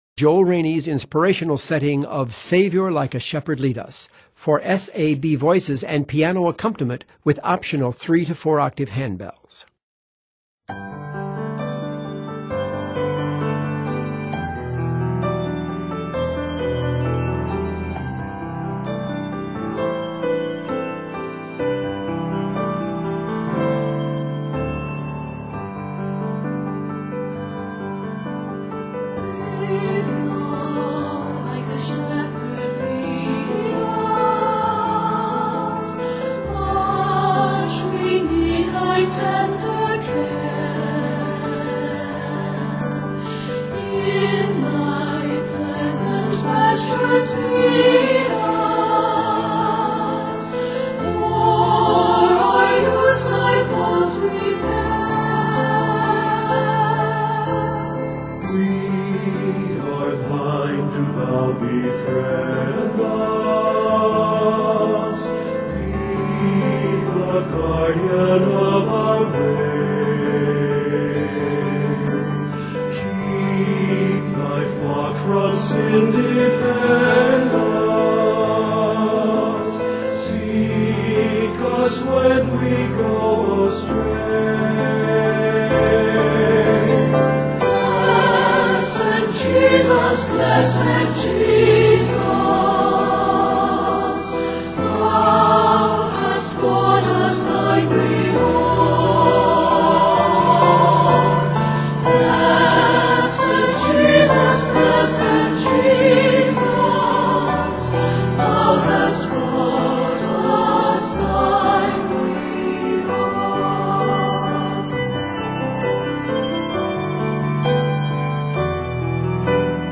Using a new melody with an expressive piano accompaniment